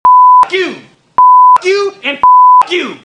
bleep